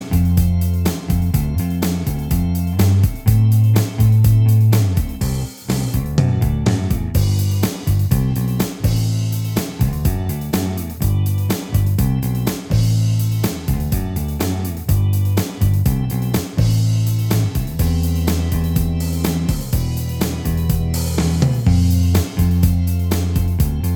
Minus Guitars Indie / Alternative 4:03 Buy £1.50